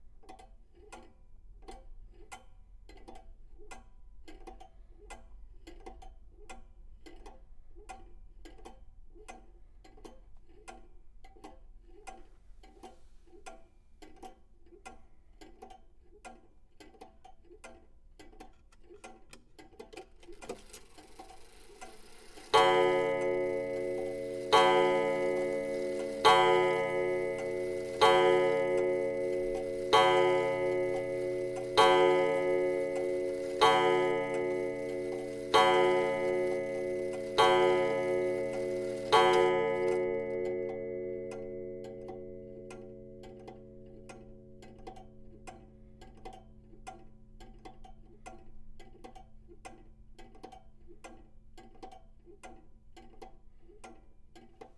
1840年祖父钟 " 关门内钟声响起
描述：一个非常古老的祖父钟的滴答声，大约在1840年制造。麦克风在柜子里，门关着，非常接近机械。可以循环播放。
标签： 环境-sounds的研究 时钟 爷爷 滴答 滴答
声道立体声